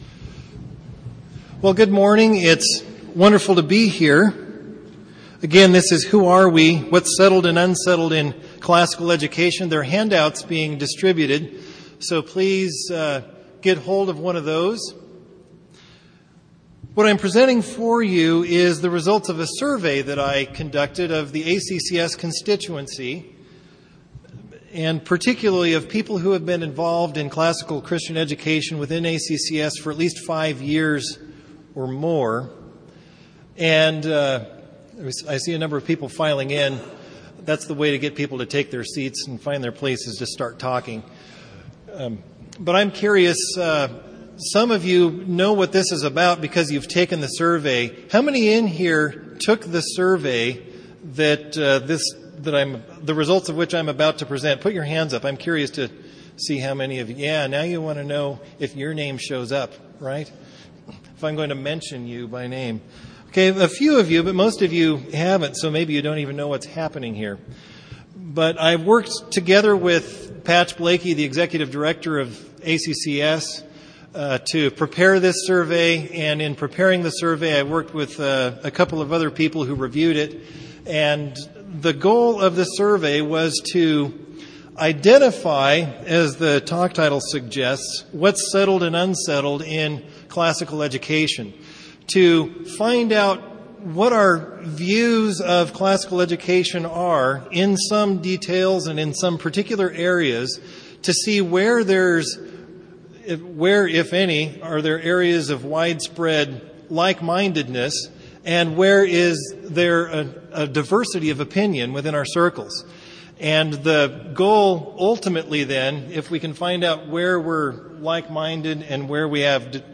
2008 Workshop Talk | 1:02:54 | All Grade Levels, General Classroom